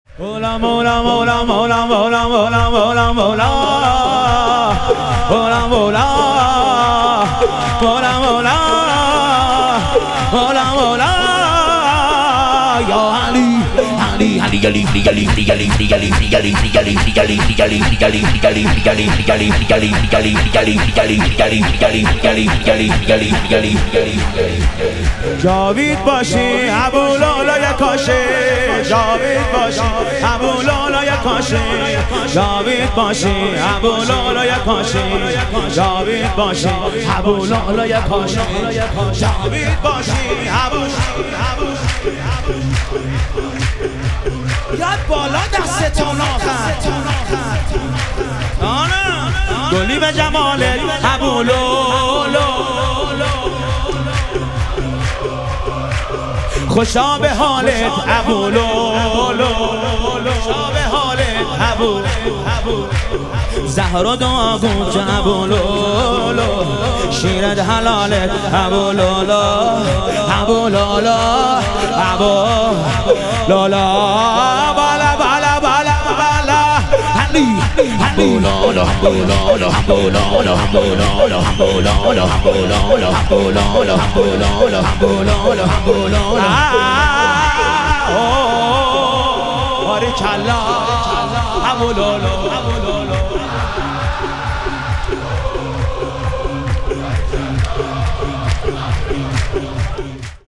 ظهور وجود مقدس رسول اکرم و امام صادق علیهم السلام - شور